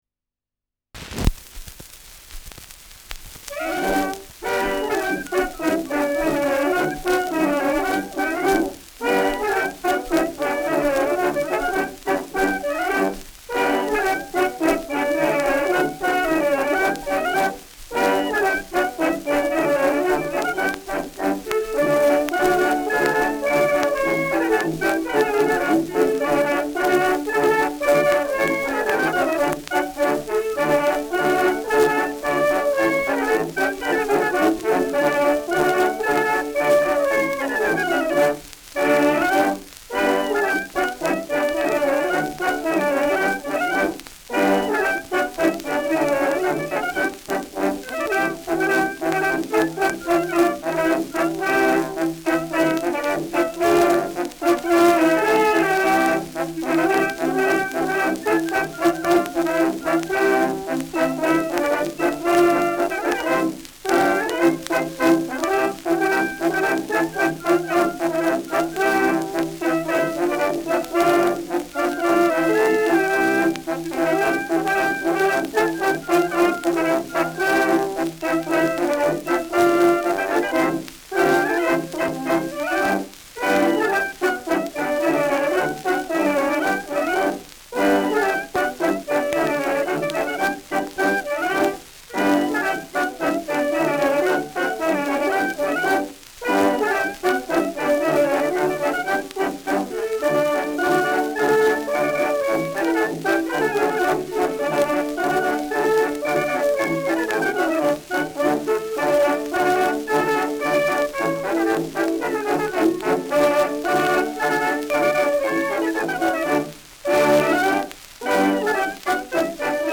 Schellackplatte
Stärkeres Grundrauschen : Gelegentlich leichtes Knacken
Stadtkapelle Fürth (Interpretation)